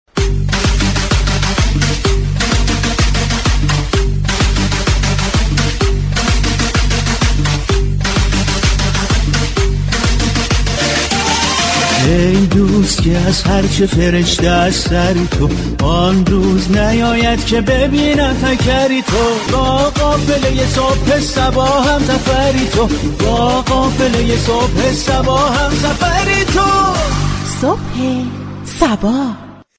رادبو صبا در برنامه زنده "صبح صبا " به گرامیداشت دهه وقف می پردازد.
برنامه صبح صبا نیز با پخش نمایش های زنده ، اخبار روز ایران و جهان و تعامل با مخاطبان روز دوشنبه در جهت گشترش فرهنگ وقف راهی آنتن می شود .